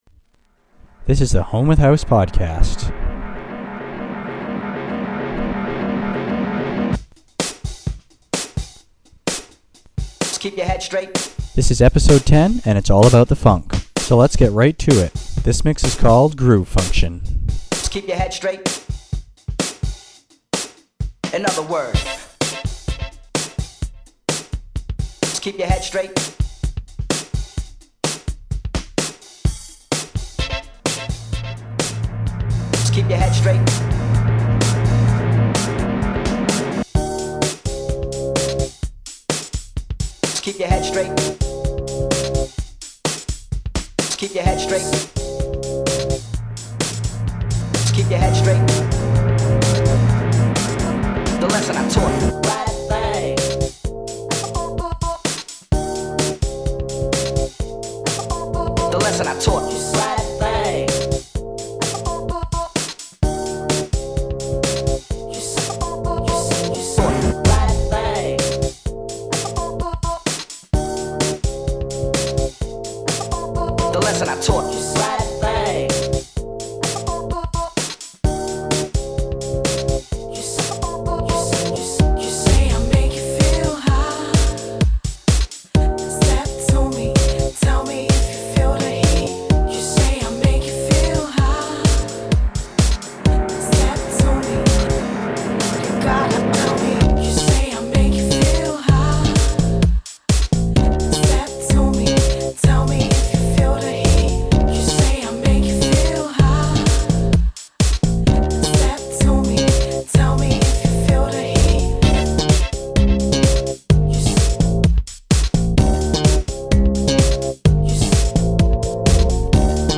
Vibe: Funky House , Jackin House , Pumping House